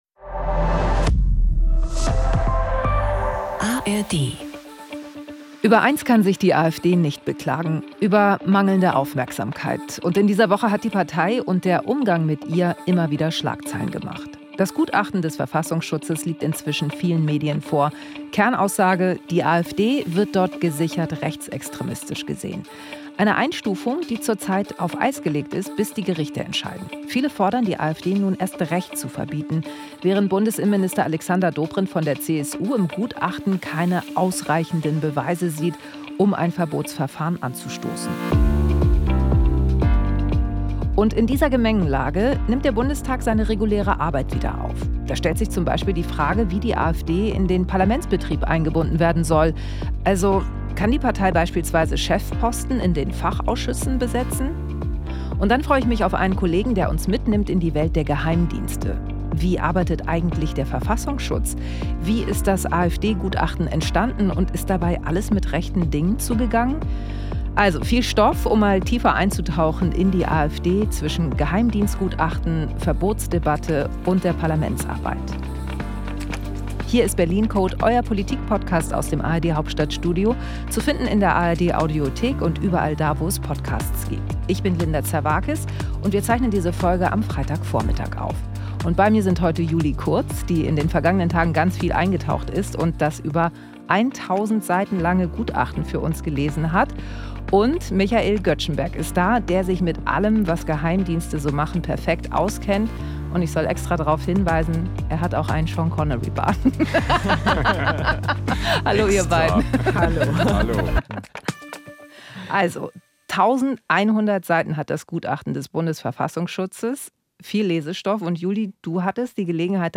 Linda Zervakis schaut mit den ARD-Korrespondentinnen und -korrespondenten jede Woche hinter die Kulissen der Bundespolitik. Zusammen entschlüsseln sie Reden, Texte, Vorgänge und Entscheidungen, analysieren die bedeutenden Themen und greifen das auf, was im Nachrichtenalltag manchmal zu kurz kommt.